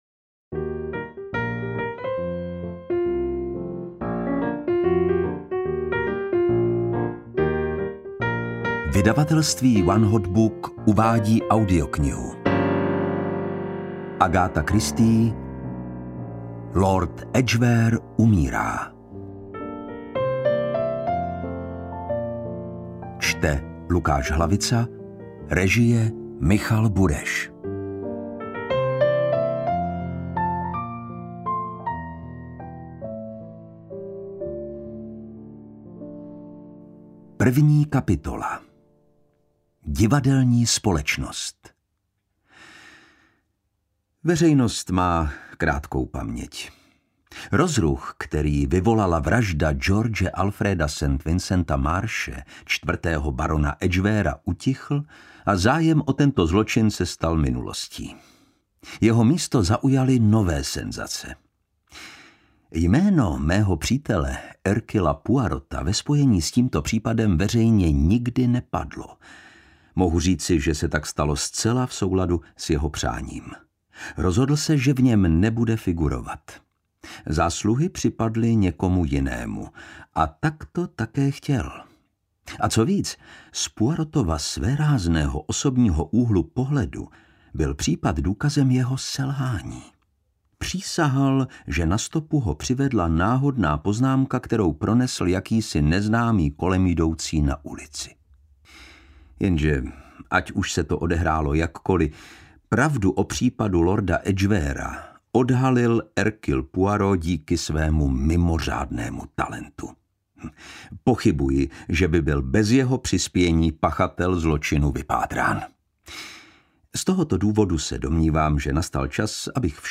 Interpret:  Lukáš Hlavica